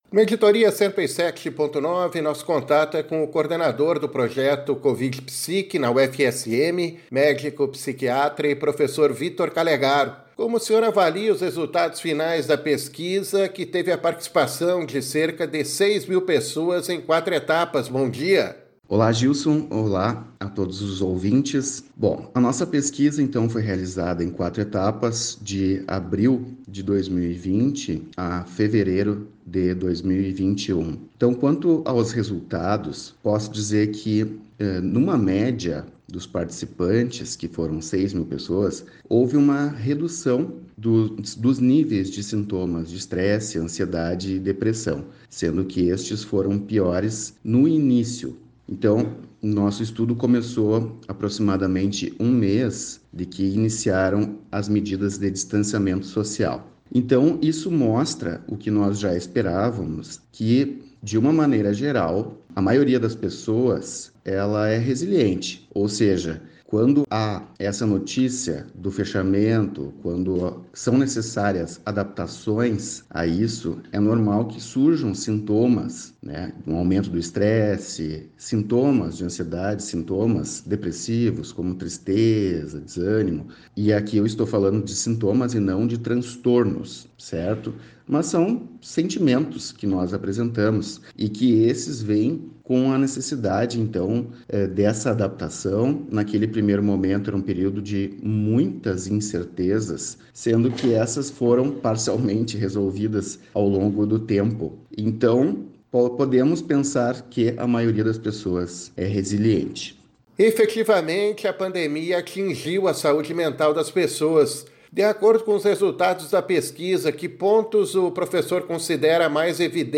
no programa Editoria 107.9, da Rádio UniFM
A entrevista pode ser conferida a seguir: